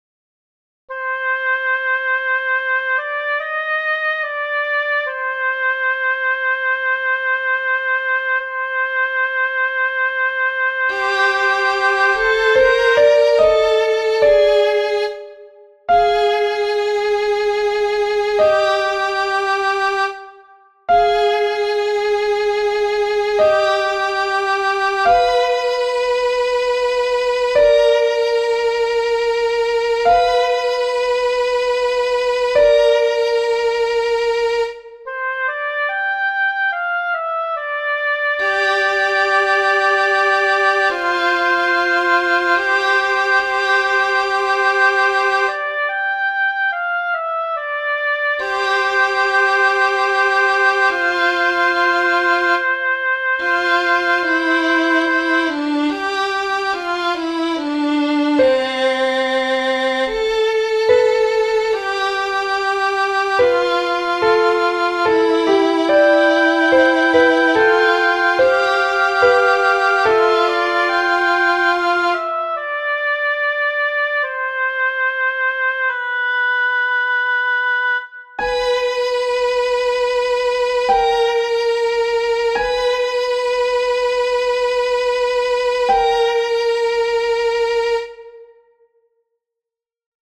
In these recordings, the higher voice is a piano, lower voice is a violin. If it is helpful, I included a soloist part, which sounds like an oboe.
THESE ARE QUITE LOUD.
SOPRANO 1 AND ALTO 1, PAGE 2
agnusdei-pg2-s1-a1.mp3